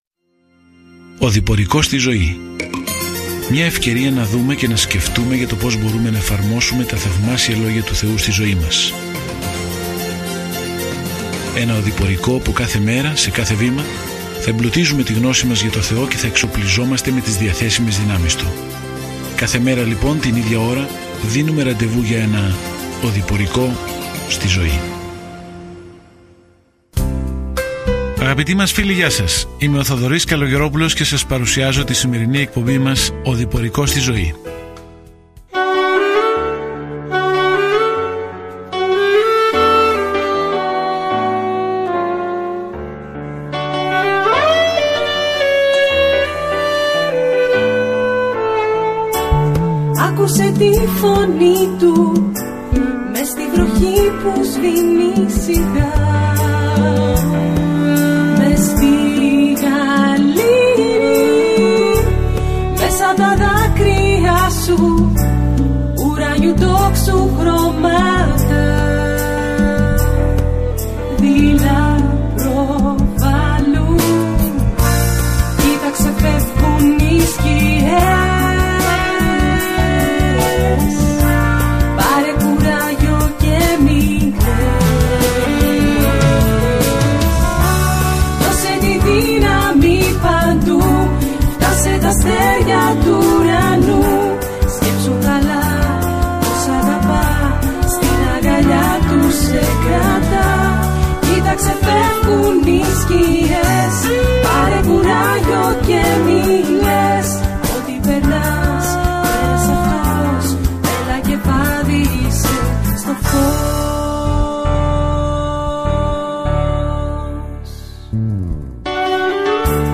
Κείμενο ΙΩΒ 33:29-33 ΙΩΒ 34 ΙΩΒ 35 Ημέρα 19 Έναρξη αυτού του σχεδίου Ημέρα 21 Σχετικά με αυτό το σχέδιο Σε αυτό το δράμα του ουρανού και της γης, συναντάμε τον Ιώβ, έναν καλό άνθρωπο, στον οποίο ο Θεός επέτρεψε στον Σατανά να επιτεθεί. όλοι έχουν τόσες πολλές ερωτήσεις σχετικά με το γιατί συμβαίνουν άσχημα πράγματα. Καθημερινά ταξιδεύετε στον Ιώβ καθώς ακούτε την ηχητική μελέτη και διαβάζετε επιλεγμένους στίχους από τον λόγο του Θεού.